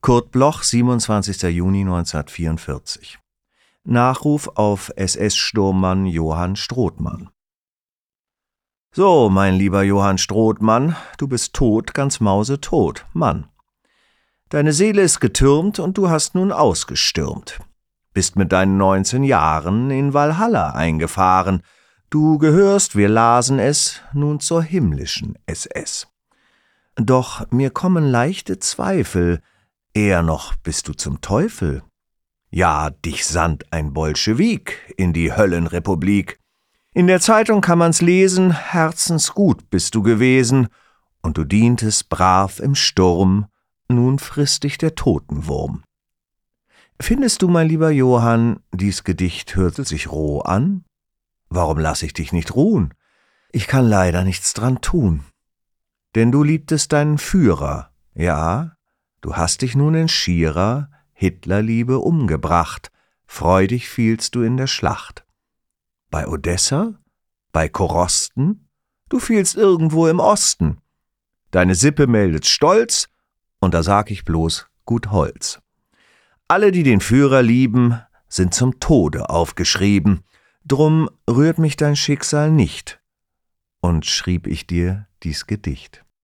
Michael Maertens (* 1963) ist ein deutscher Schauspieler.